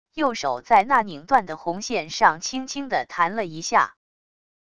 右手在那拧断的红线上轻轻的弹了一下wav音频生成系统WAV Audio Player